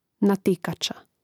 natìkača natikača